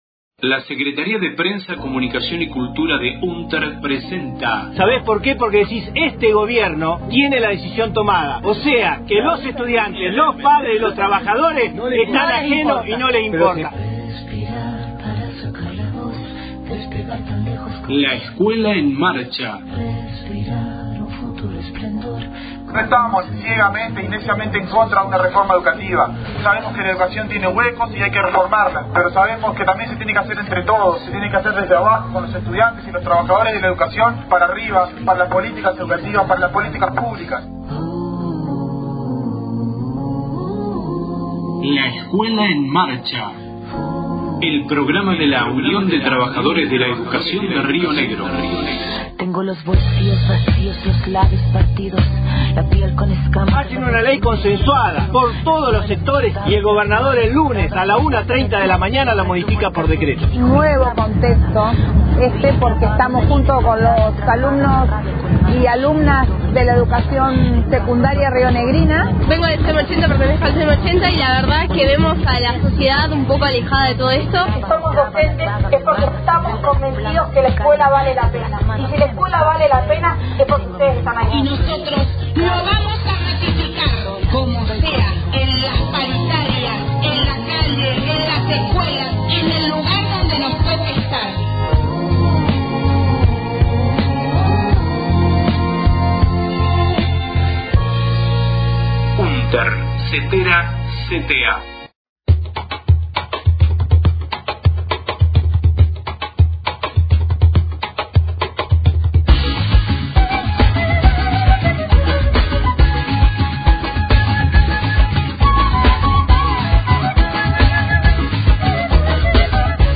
La Escuela en Marcha La Escuela en Marcha Radio Derechos Humanos Género e Igualdad de Oportunidades